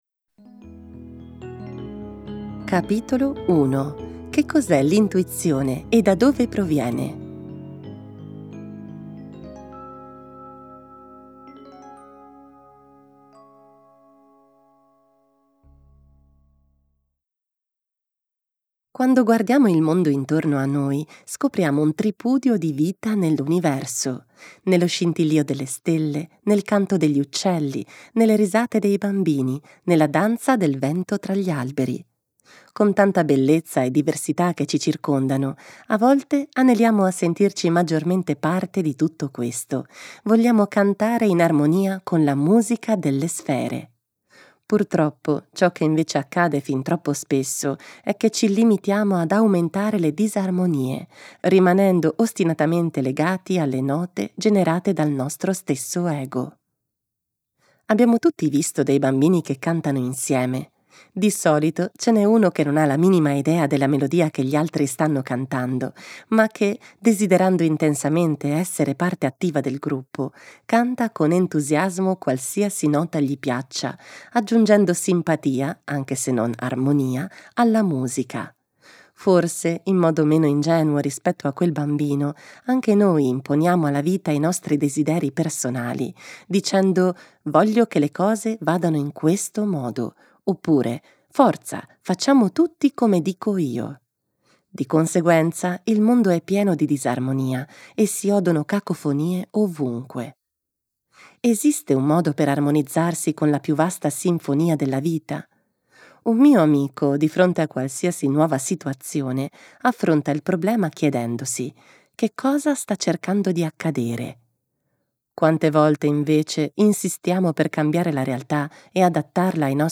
Lettore